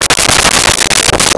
Chickens In Barn 05
Chickens in Barn 05.wav